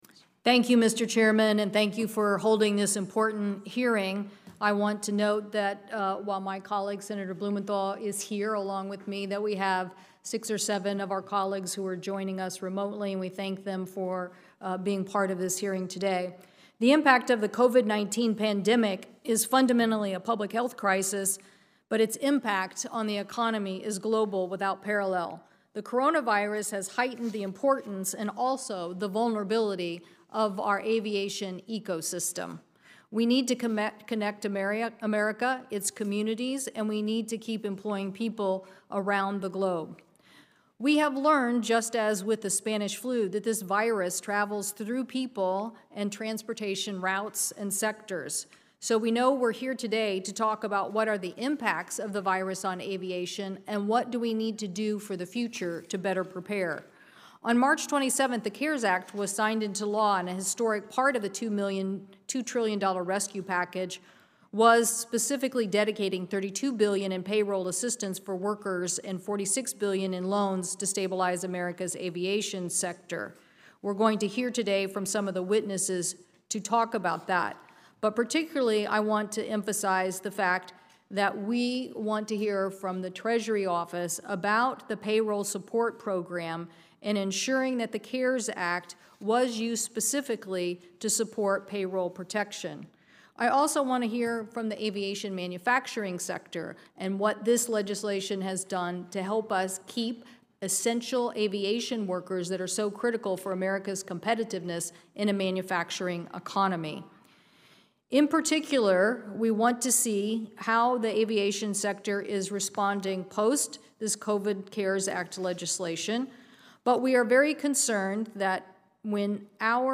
opening-statement-commerce-aviation-audio&download=1